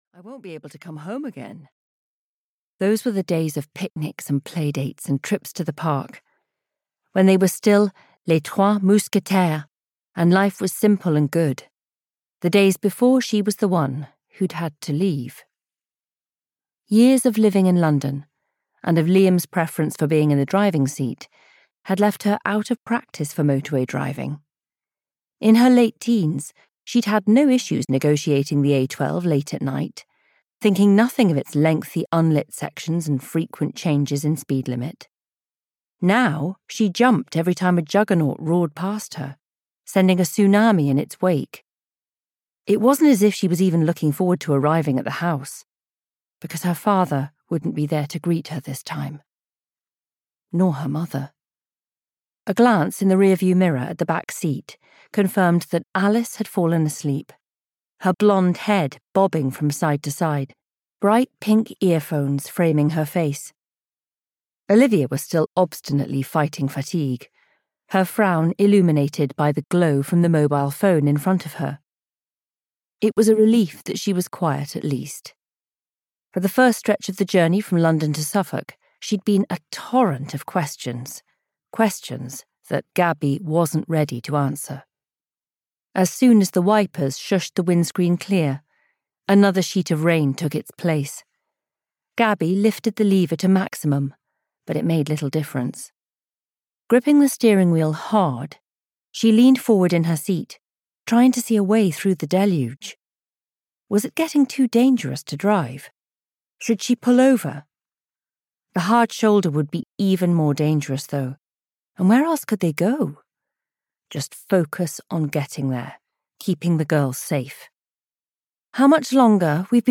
My Stepmother's Secret (EN) audiokniha
Ukázka z knihy